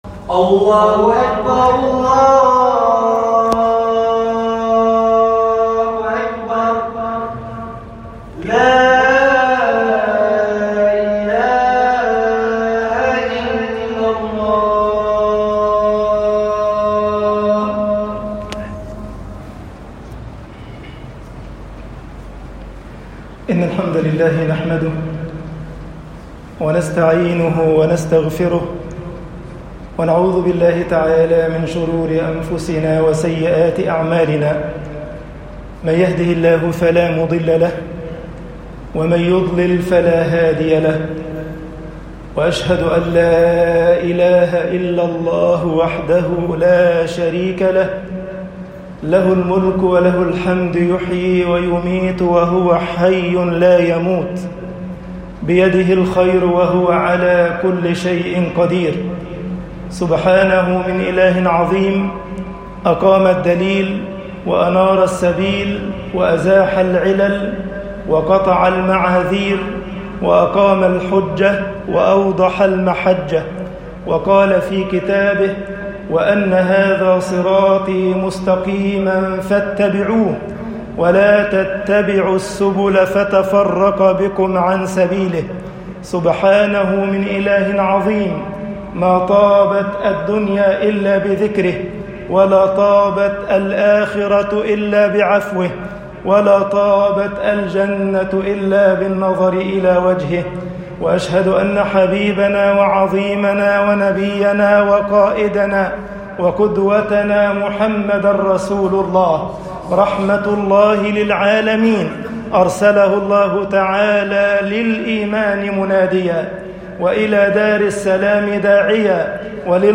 خطب الجمعة - مصر دعوةٌ للأمل وبشارةٌ بقبول العمل طباعة البريد الإلكتروني التفاصيل كتب بواسطة